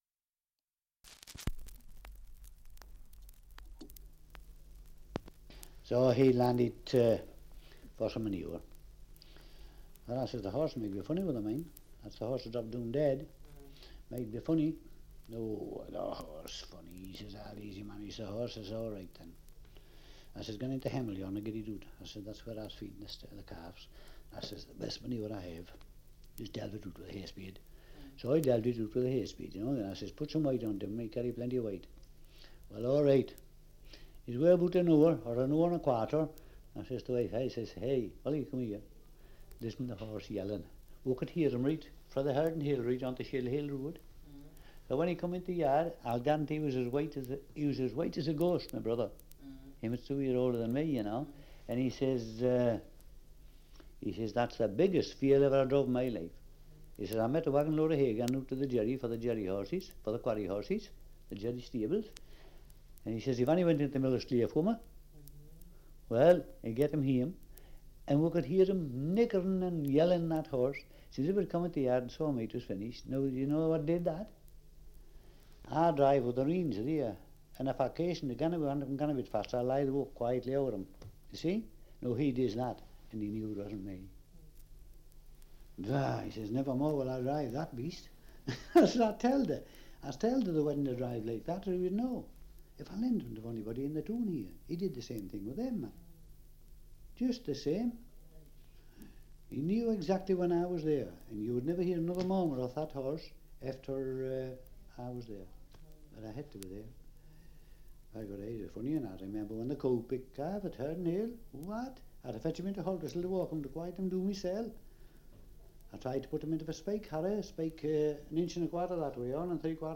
2 - Survey of English Dialects recording in Haltwhistle, Northumberland
78 r.p.m., cellulose nitrate on aluminium